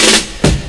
02_05_drumbreak.wav